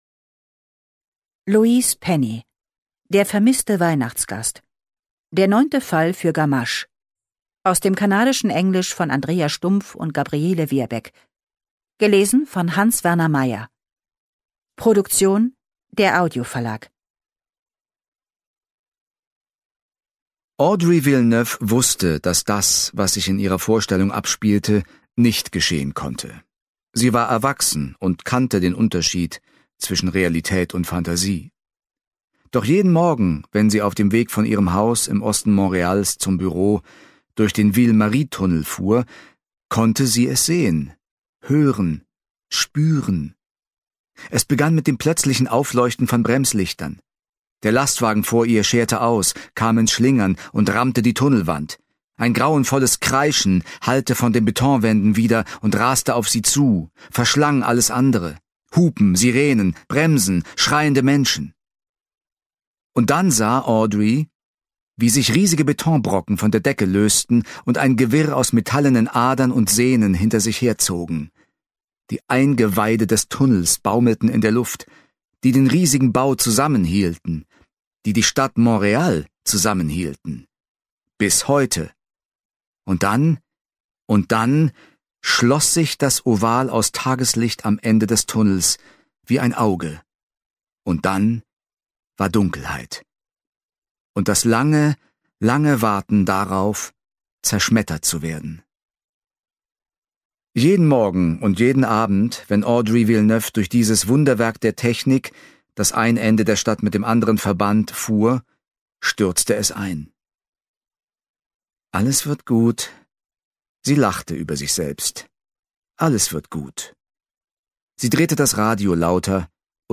Hans-Werner Meyer (Sprecher)
Ungekürzte Lesung